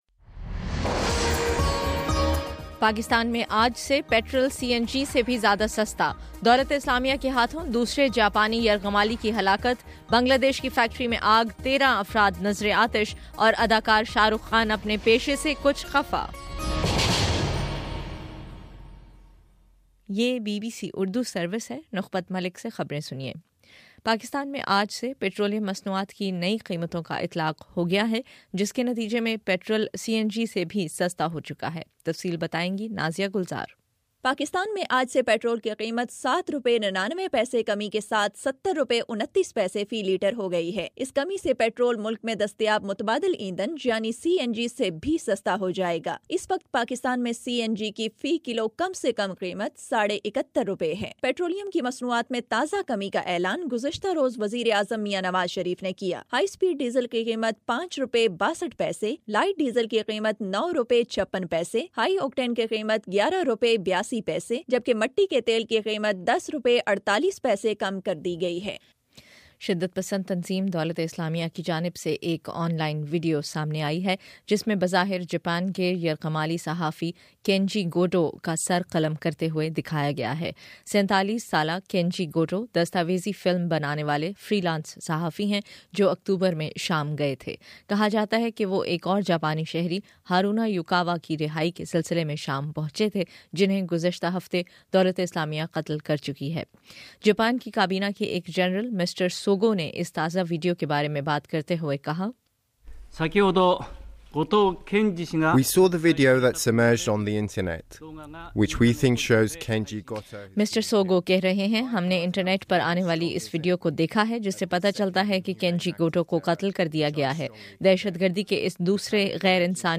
فروری 01: صبح نو بجے کا نیوز بُلیٹن